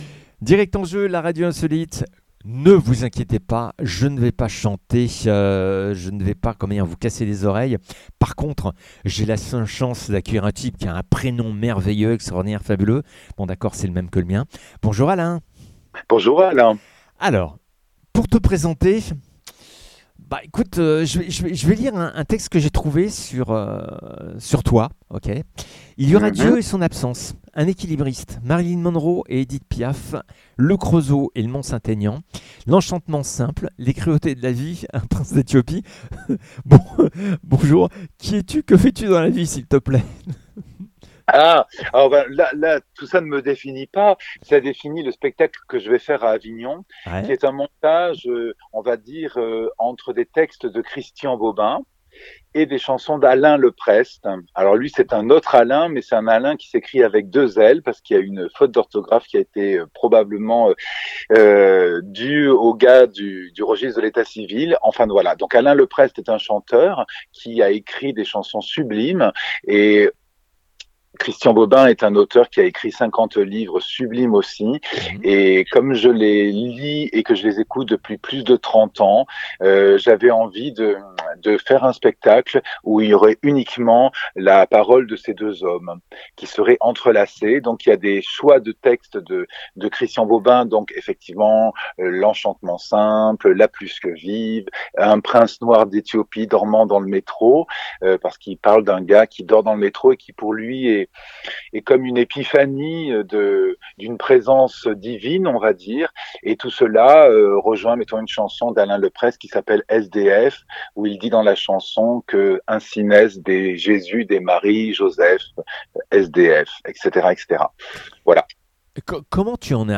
au chant, pîano et jeu pour Constellation Bobin Leprest